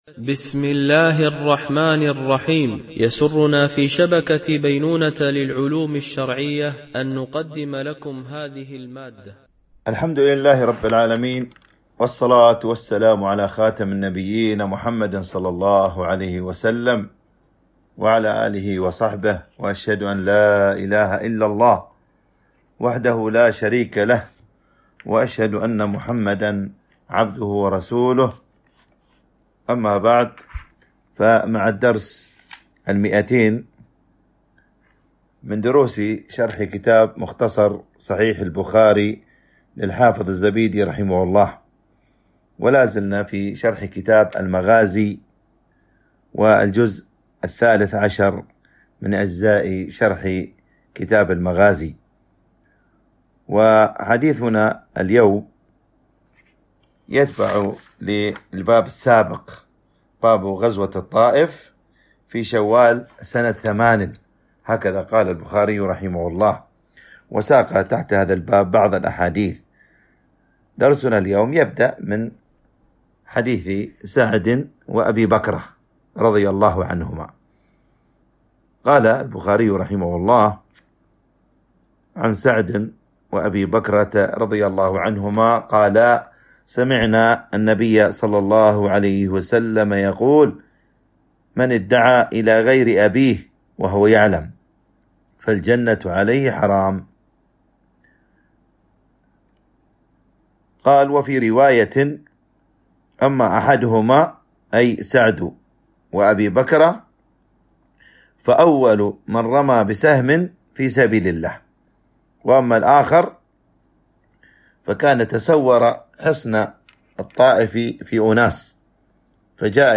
شرح مختصر صحيح البخاري ـ الدرس 200 ( كتاب المغازي ـ الجزء الثالث عشر- الحديث 1670-1675)